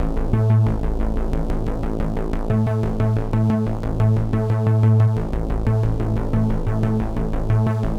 Index of /musicradar/dystopian-drone-samples/Droney Arps/90bpm
DD_DroneyArp3_90-A.wav